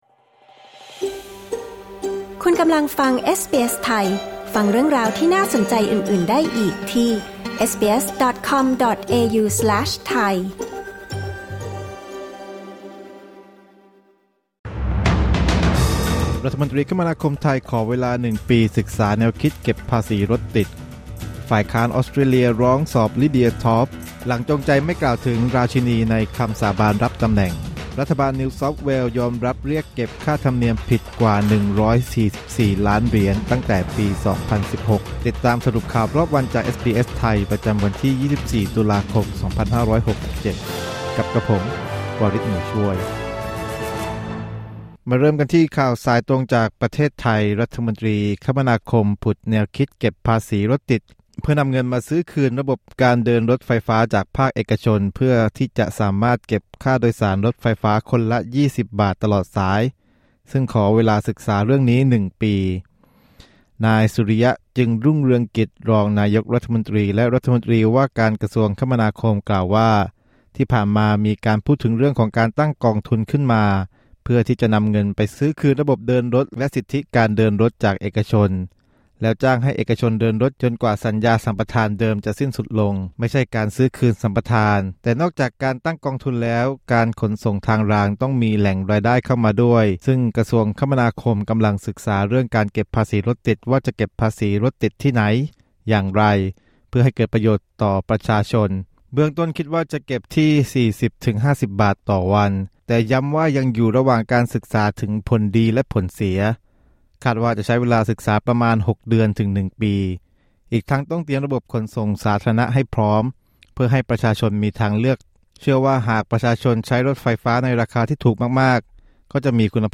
สรุปข่าวรอบวัน 24 ตุลาคม 2567